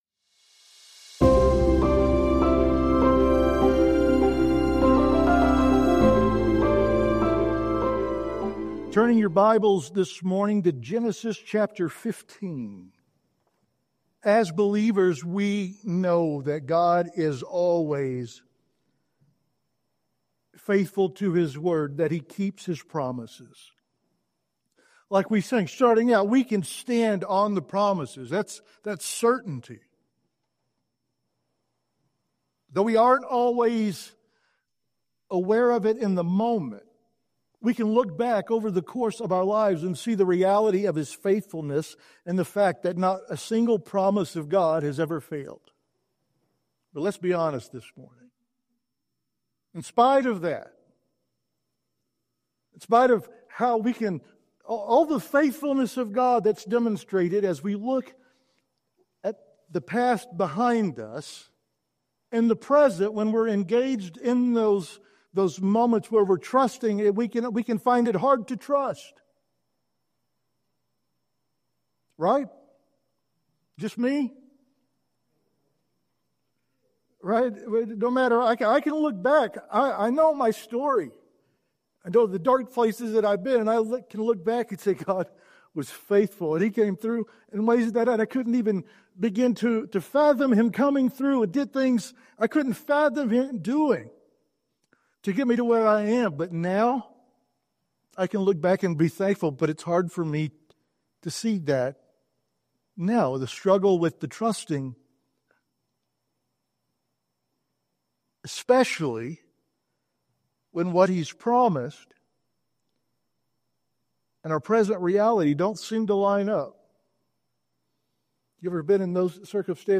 Sermons | Big Horn Baptist Church